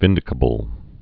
(vĭndĭ-kə-bəl)